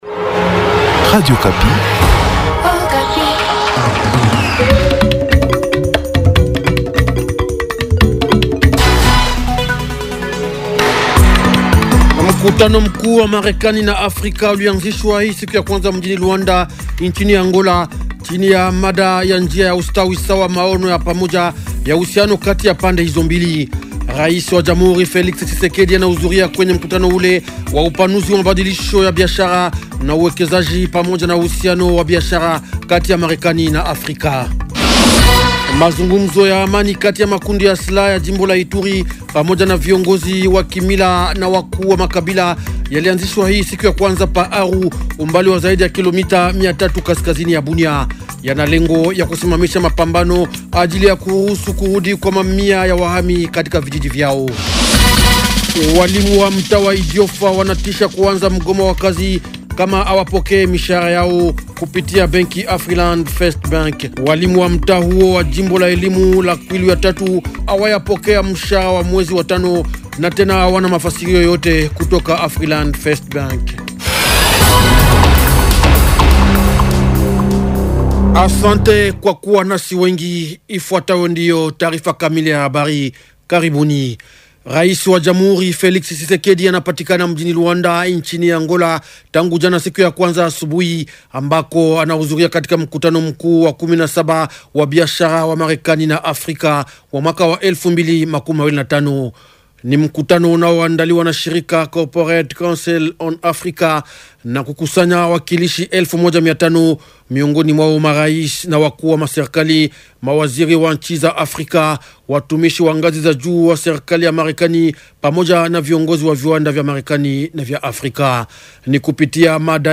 Journal Swahili